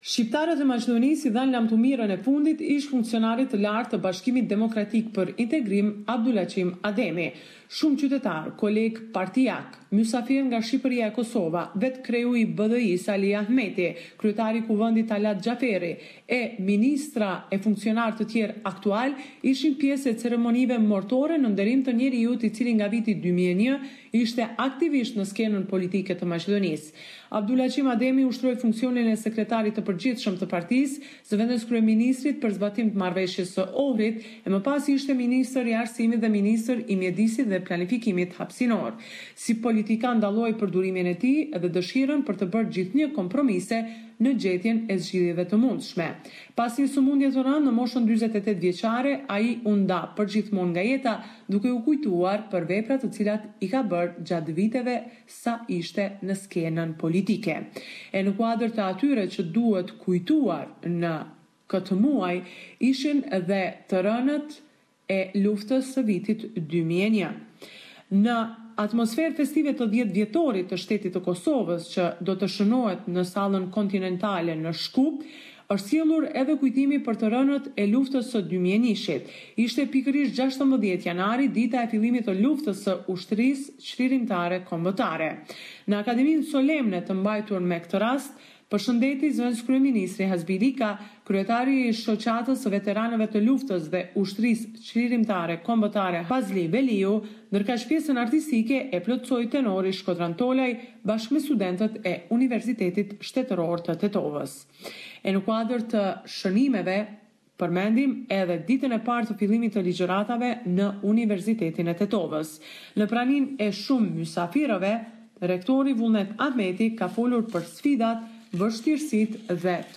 This is a report summarising the latest developments in news and current affairs in Macedonia.